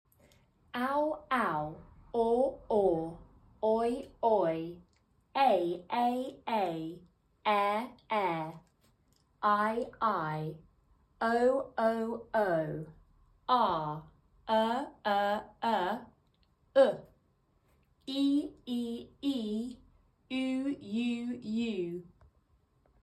Most Common Complex Phonics Sounds.
Some of these sounds are more common than alphabet phonics like "q", "x", and "z". For a small number of these sounds, the American pronunication is slightly different.